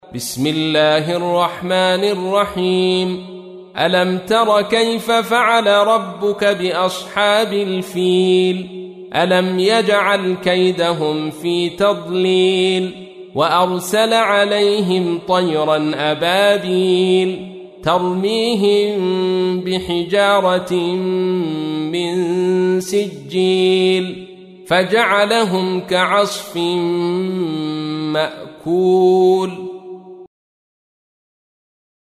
تحميل : 105. سورة الفيل / القارئ عبد الرشيد صوفي / القرآن الكريم / موقع يا حسين